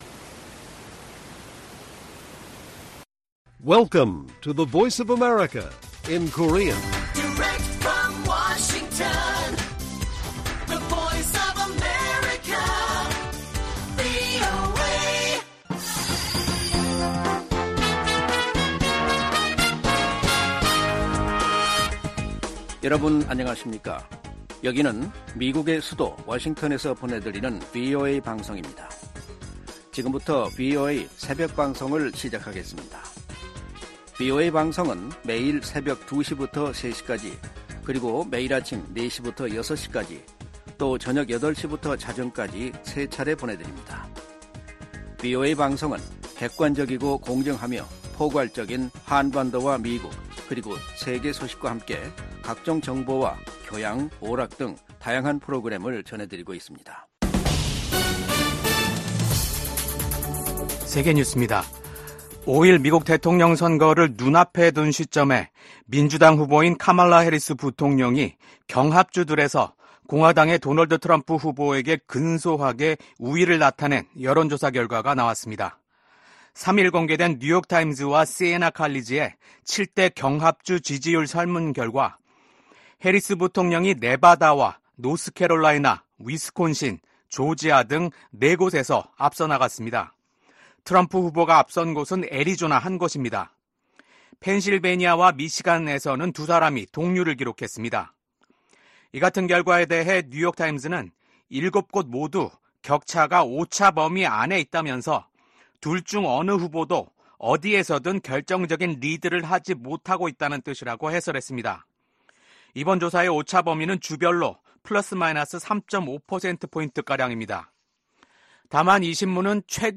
VOA 한국어 '출발 뉴스 쇼', 2024년 11월 5일 방송입니다. 북한의 러시아 파병 문제가 국제적인 중대 현안으로 떠오른 가운데 북러 외교수장들은 러시아의 우크라이나 전쟁을 고리로 한 결속을 강조했습니다. 미국과 한국의 외교, 국방 수장들이 북러 군사협력 심화와 북한의 대륙간탄도미사일 발사를 강력히 규탄했습니다. 10개월 만에 재개된 북한의 대륙간탄도미사일 발사를 규탄하는 국제사회의 목소리가 이어지고 있습니다.